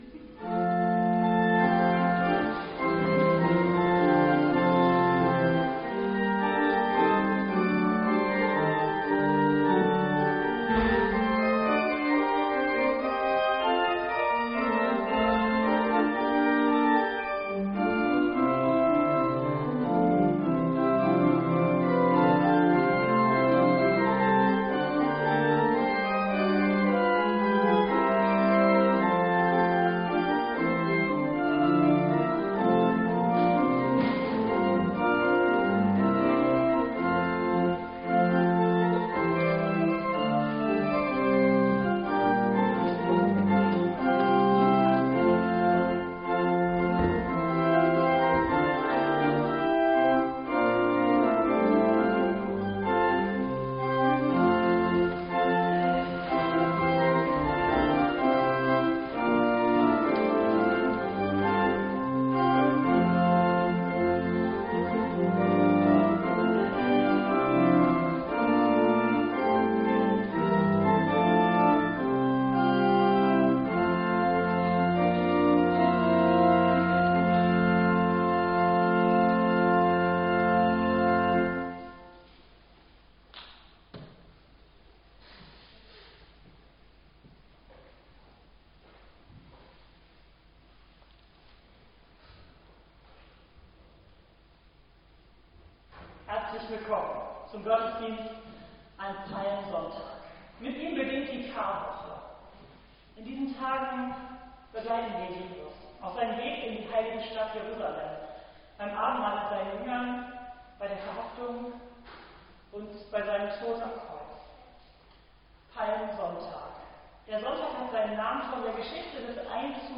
Der Gottesdienst am Palmsonntag, dem Auftakt der Karwoche, können Sie hier nachhören.